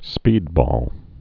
(spēdbôl)